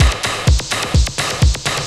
TECHNO125BPM 20.wav